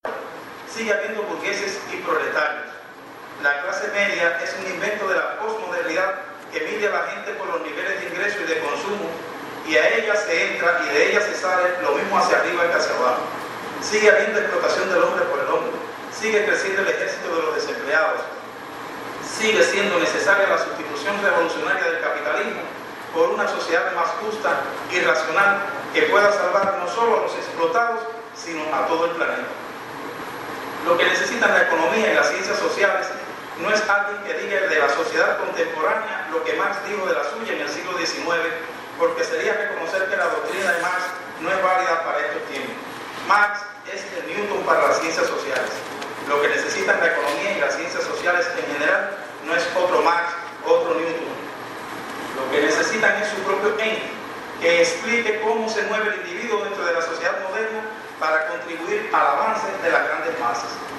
Investigadores de las ciencias sociales y humanísticas de Granma recordaron al economista y filósofo Karl Marx durante la IX Conferencia de las Ciencias sociales y humanísticas celebrada en Bayamo.